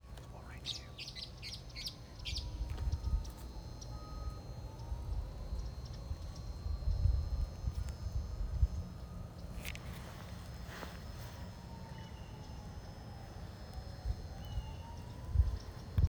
Couch's Kingbird